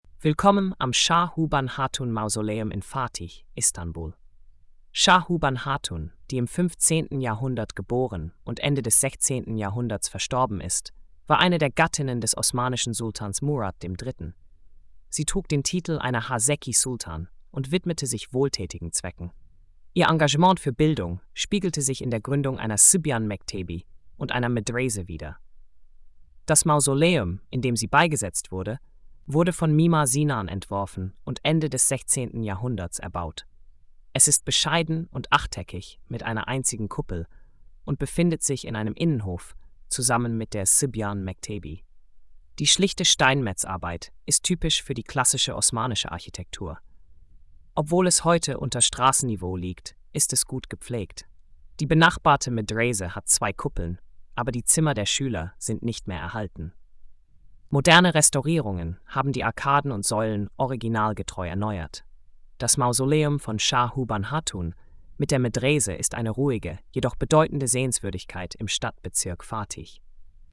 Audio Erzählung: